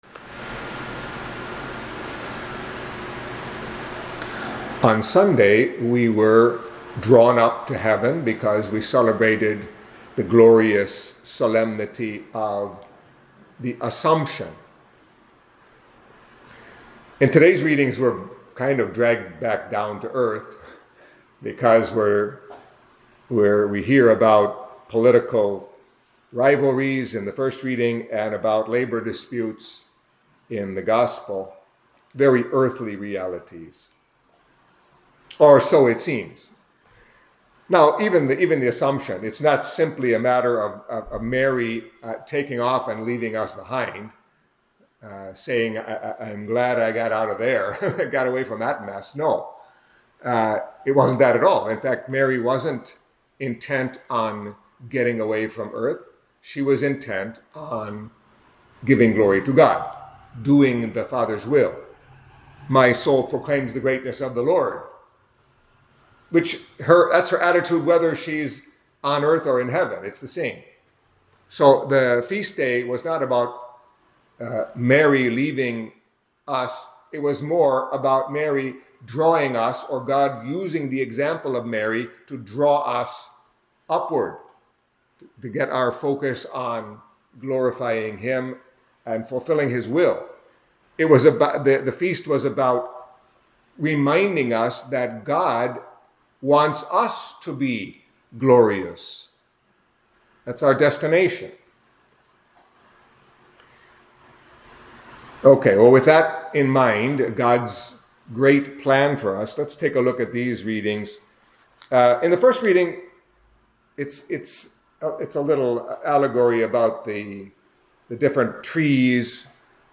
Catholic Mass homily for Wednesday of the 20th Week in Ordinary Time